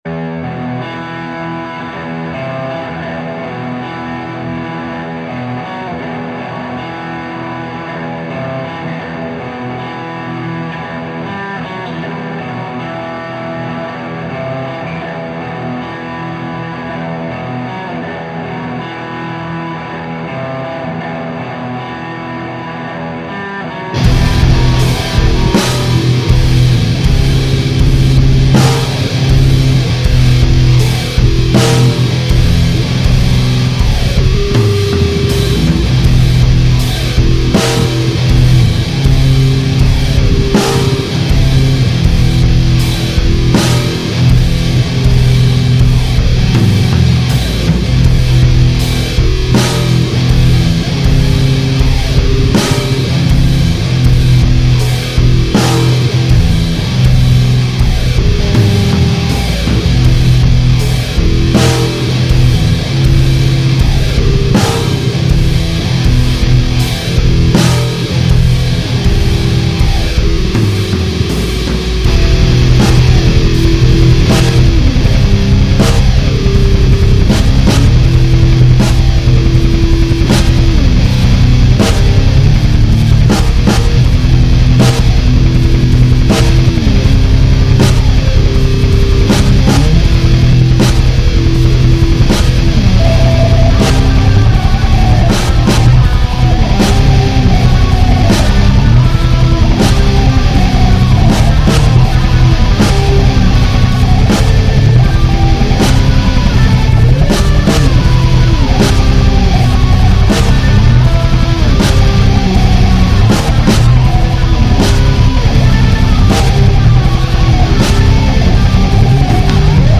Прошу заценить демо трек с моего грядущего сборника. Жанр хз, нечто похожее на дуум.